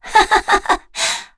Kara-Vox_Happy3.wav